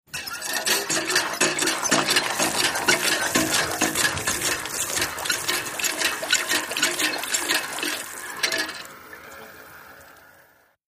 MACHINES - CONSTRUCTION HAND MANUAL WATER PUMP: EXT: Pumping hard, lever & mechanism creaks, water gushing.